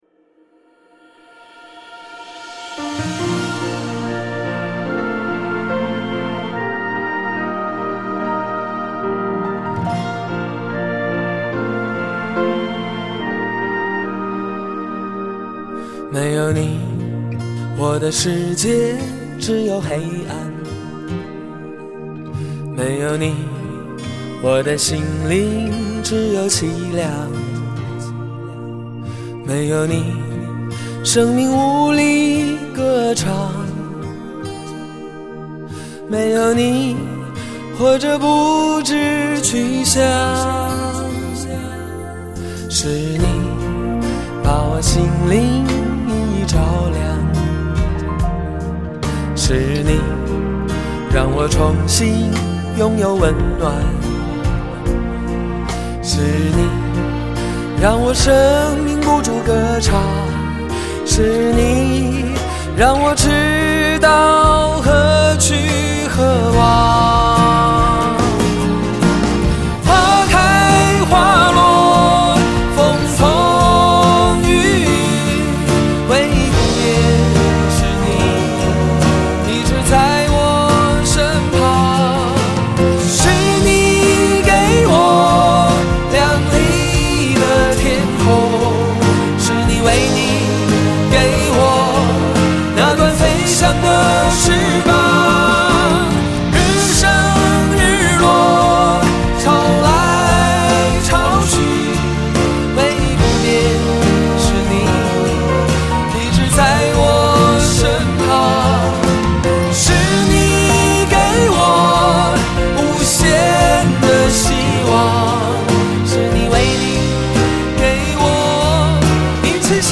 HAKA祷告敬拜MP3 启示性祷告： 持续祷告：祈求神的旨意成就在我们的身上，带领做新事！